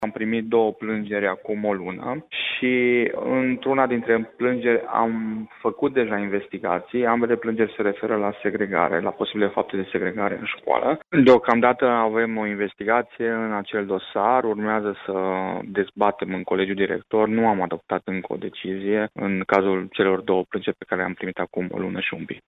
Pe de altă parte, preşedintele Consiliului Național pentru Combaterea Discriminării, Astalosz Csaba, a mai declarat pentru postul nostru de radio că există două plîngeri depuse în urmă cu o lună, care au în atenţie segregarea şcolară în două unităţi de învăţămînt din judeţul Iaşi.